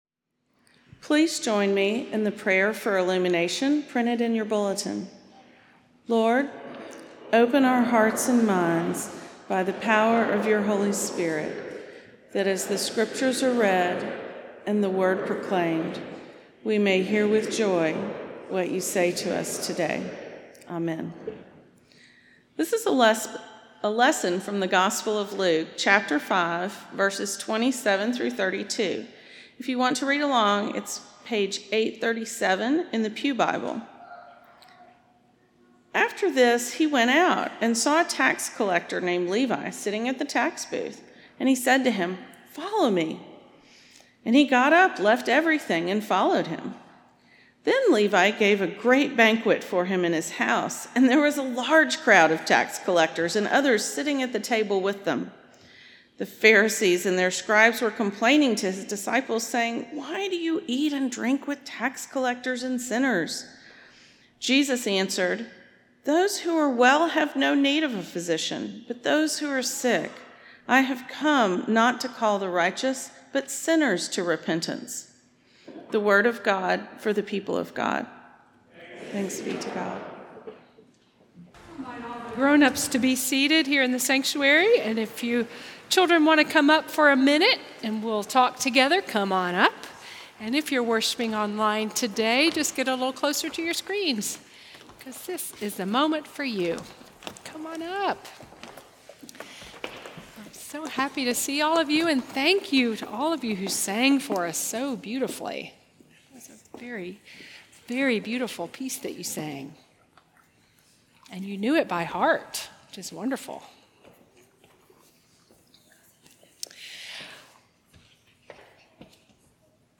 N/A Service: Sun PM Worship Type: Sermon